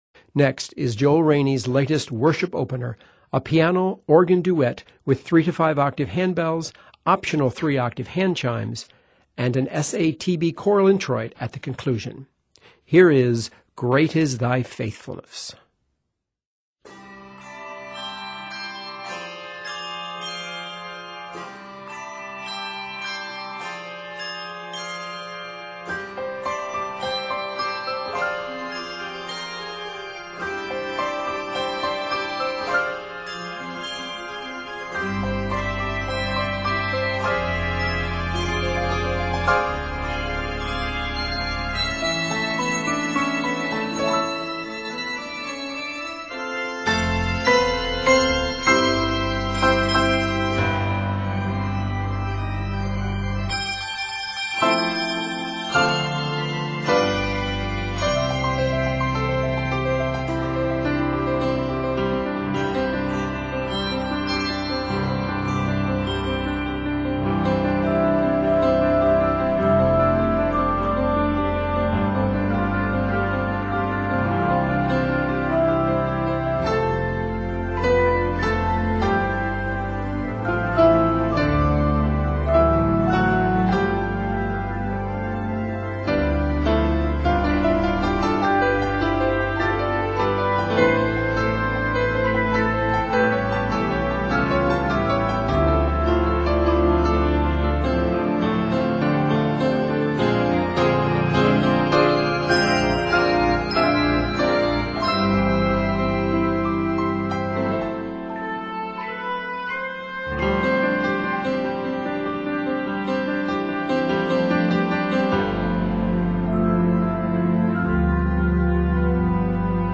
This festive worship opener
majestic tune
uplifting setting